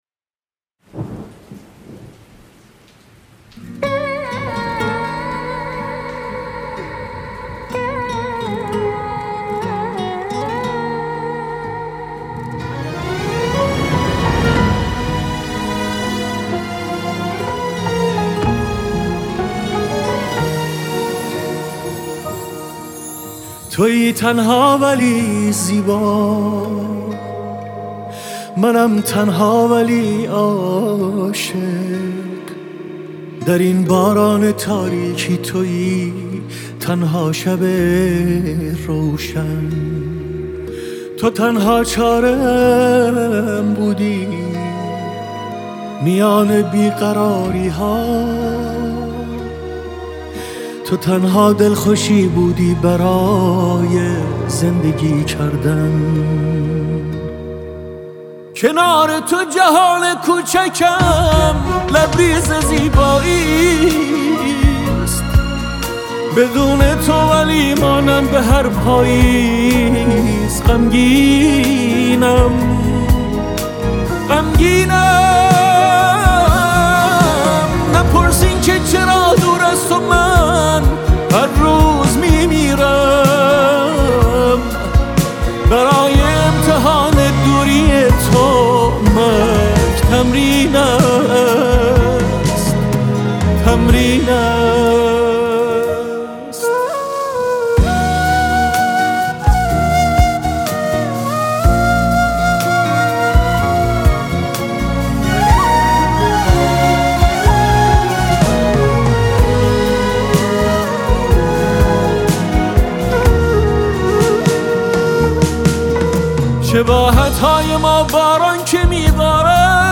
پاپ عاشقانه غمگین